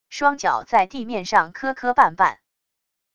双脚在地面上磕磕绊绊wav音频